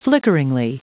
Prononciation du mot flickeringly en anglais (fichier audio)
flickeringly.wav